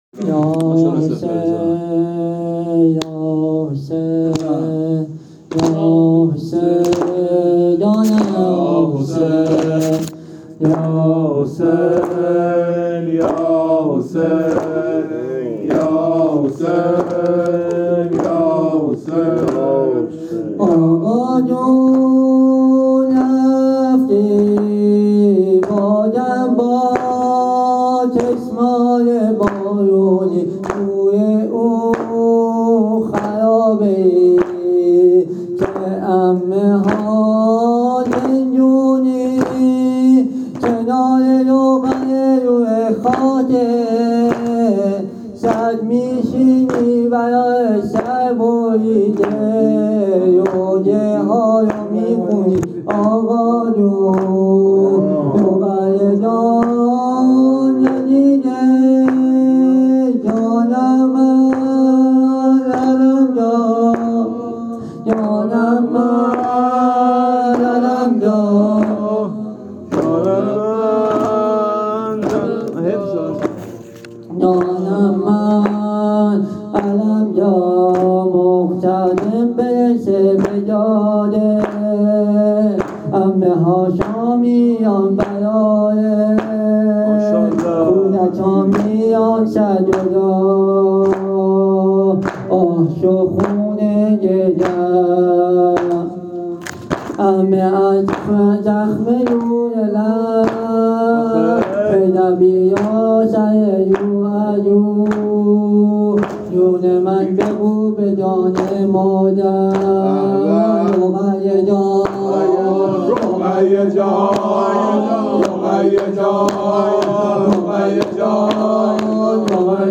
هیت هفتگی عشاق العباس تهران